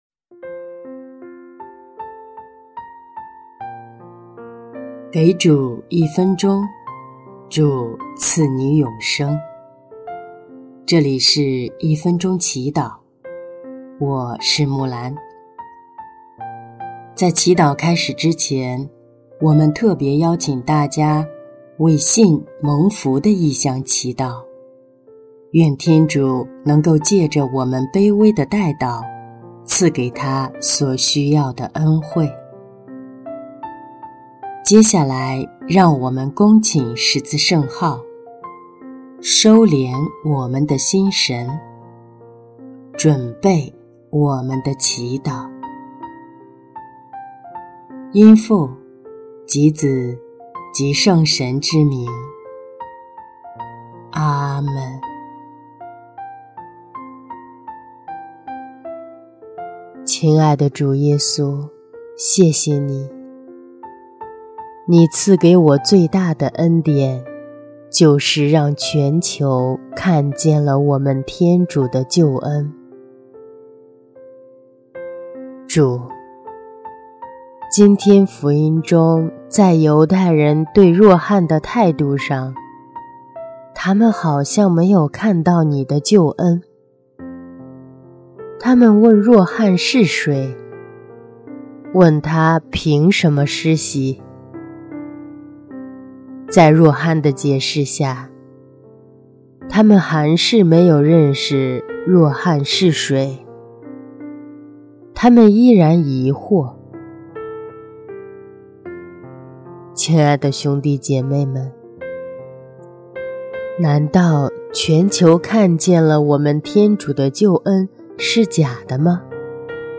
【一分钟祈祷】| 1月2日 主啊，求祢赐给我们像若翰那样的勇气